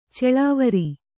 ♪ ceḷāvari